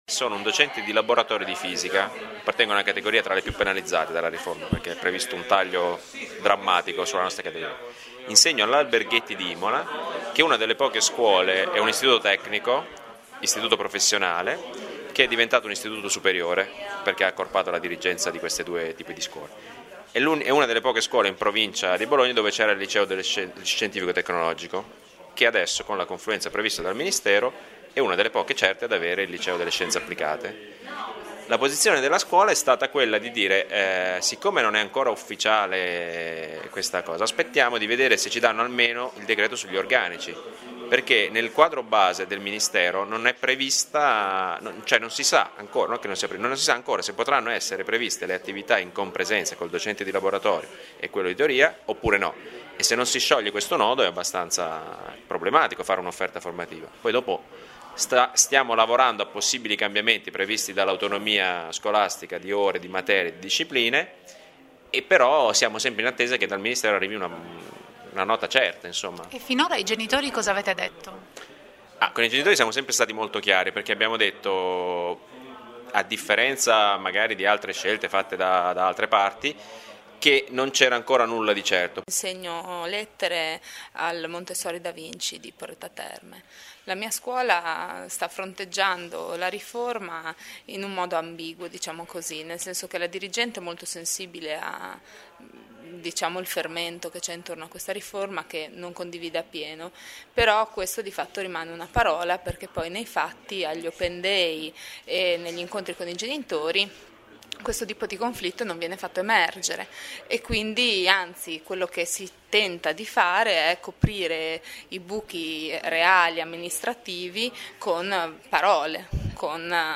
Alcuni insegnanti del Coordinamento spiegano come si sta comportando il proprio istituto: